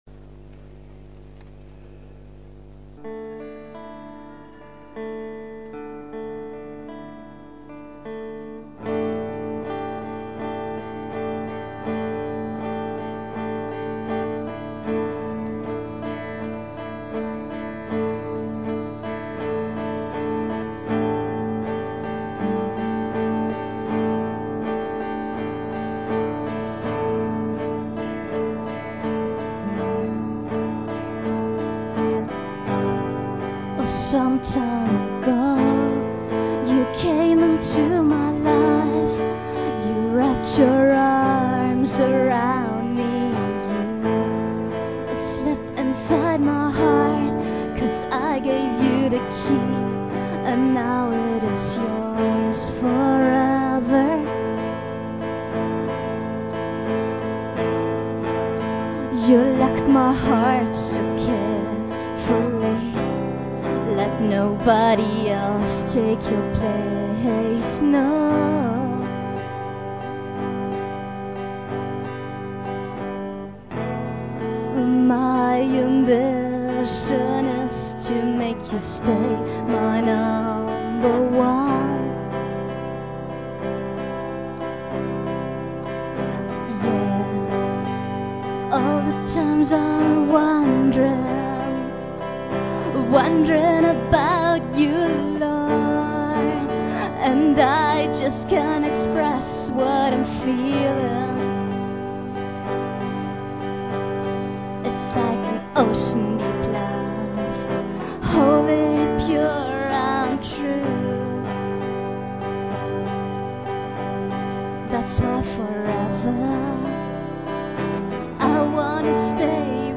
Gesang
Gesang, Gitarre
E-Piano, Keyboard